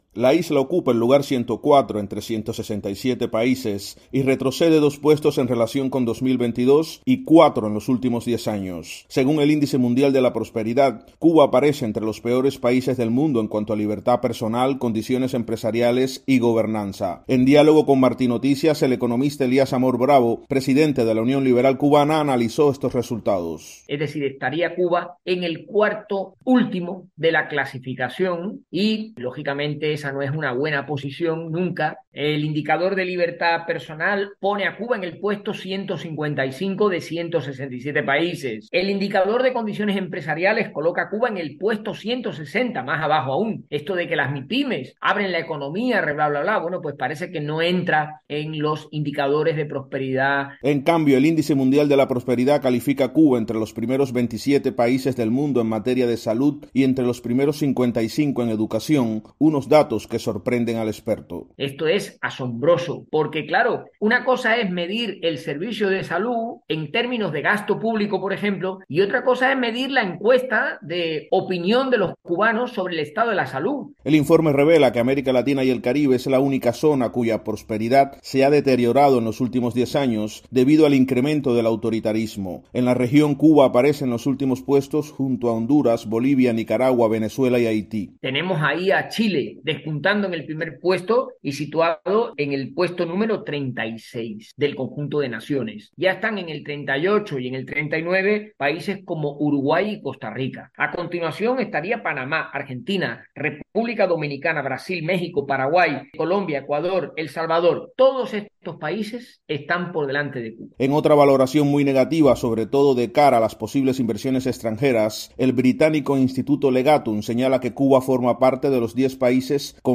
Experto analiza retroceso de Cuba en Índice Mundial de Prosperidad